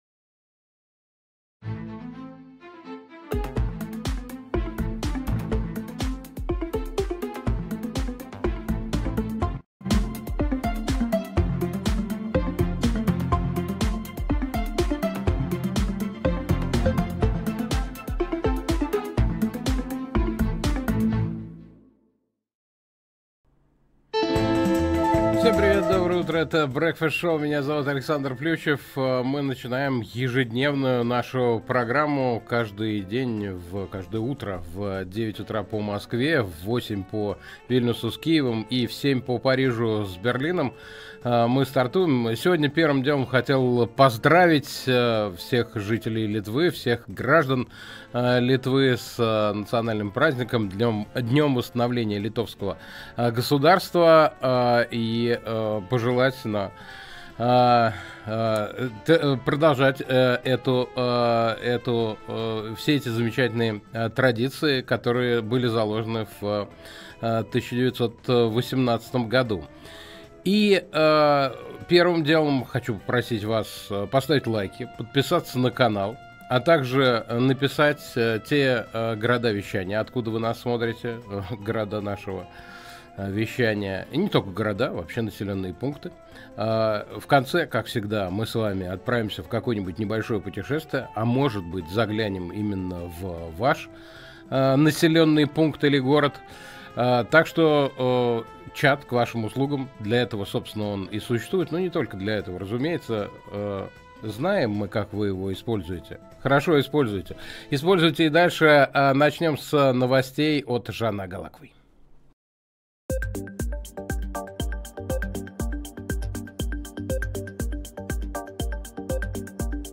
Утренний эфир с гостями